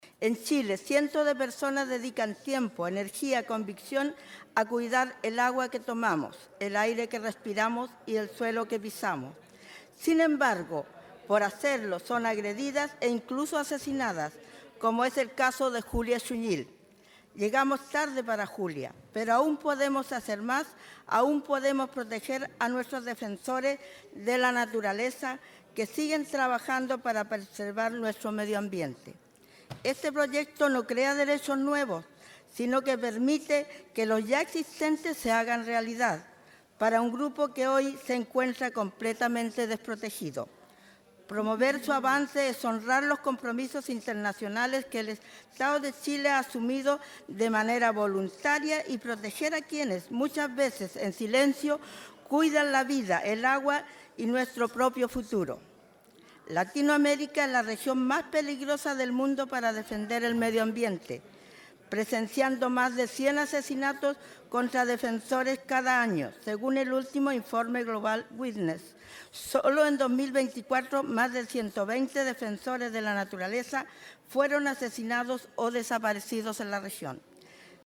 Asimismo, la diputada Clara Sagardía, del Frente Amplio y representante del Distrito 21, señaló que este proyecto “no crea derechos nuevos, sino que permite que los ya existentes se hagan realidad para un grupo que hoy se encuentra completamente desprotegido”.